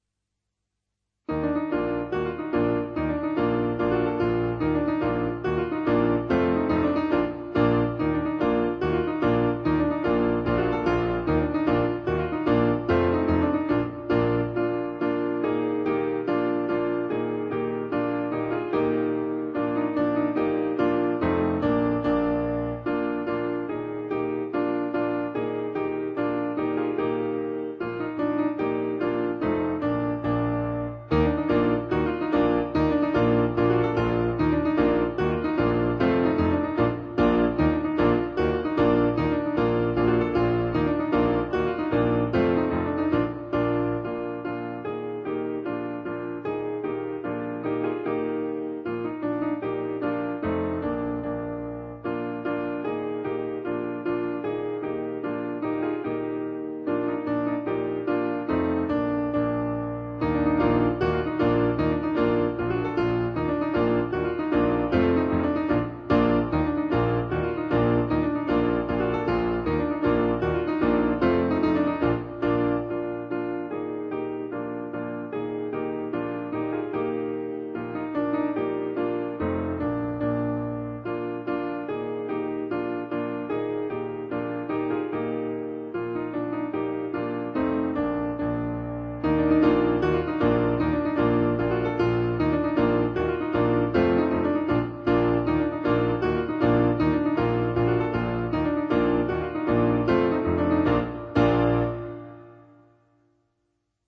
ADATTAMENTI PER PIANO